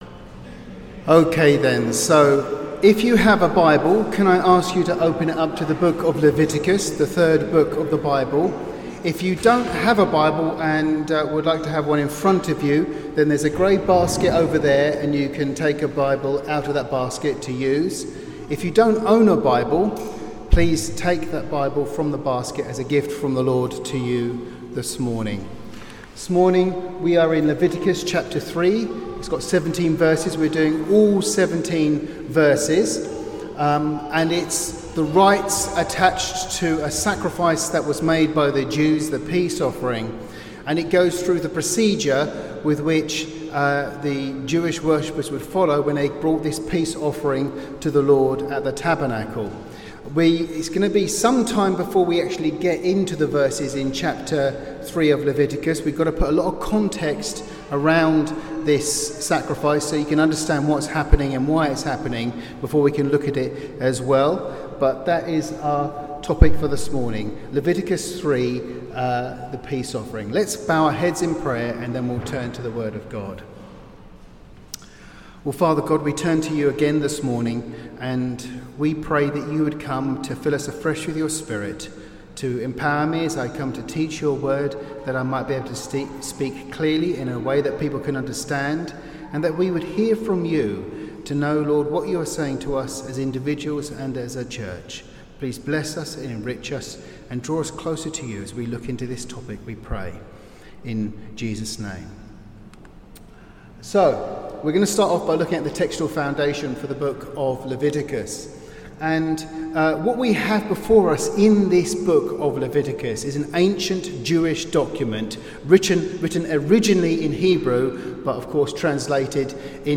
Teaching delivered on Sunday 29th September 2024